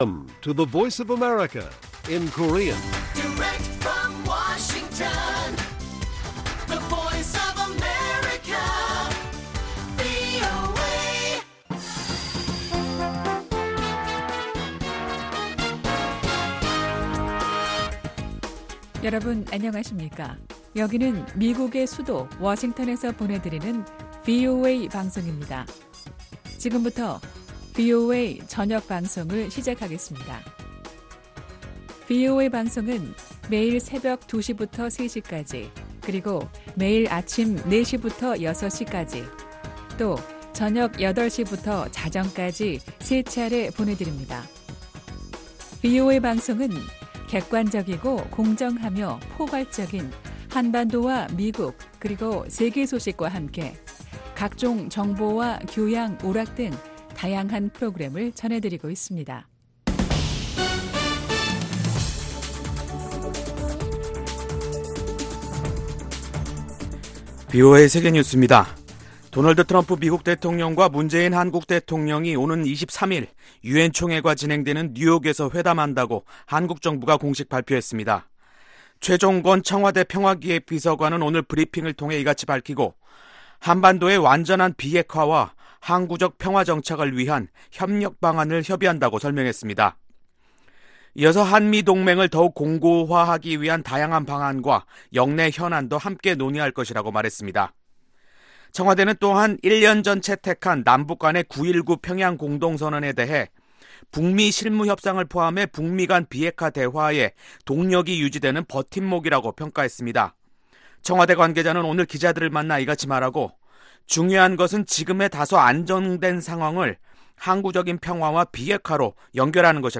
VOA 한국어 간판 뉴스 프로그램 '뉴스 투데이', 2019년 9월 19일 1부 방송입니다. 데이비드 스틸웰 미 국무부 동아시아태평양 담당 차관보가 북한이 계속 핵무기를 생산하고 있는 것으로 추정한다면서 비핵화 약속 이행을 보여줄때까지 제재를 유지해야 한다는 입장을 확인했습니다. 백악관의 신임 국가안보보좌관으로 지명된 로버트 오브라이언 특사는 전임 존 볼튼 보좌관과 대조적인 행보를 보일 것이라는 전망이 나왔습니다.